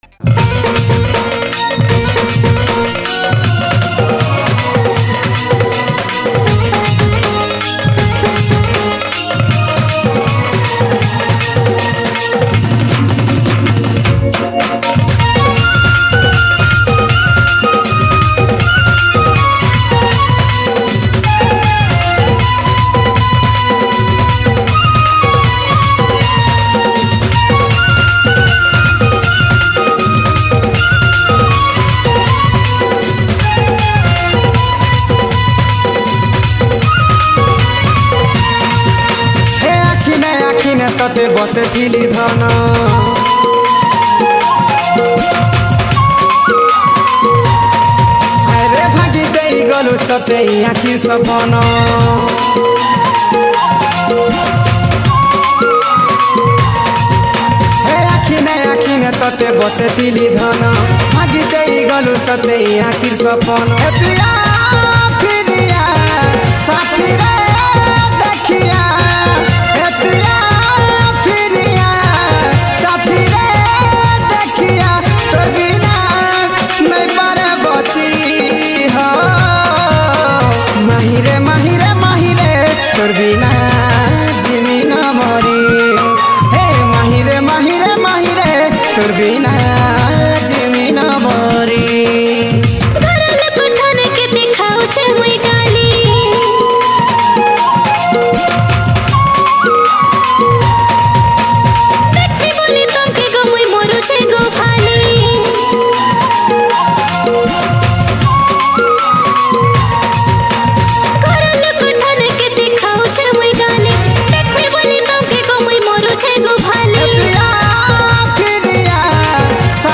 Romantic Sambalpuri Song
Sambalpuri Songs